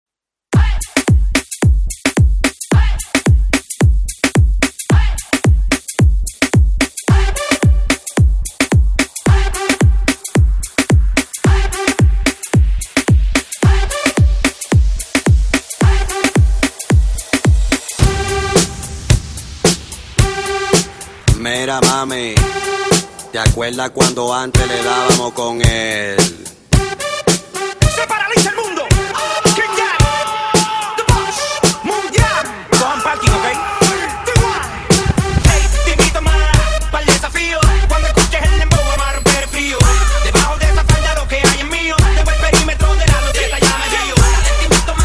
Reggaeton
REGGATON INTRO BPM 110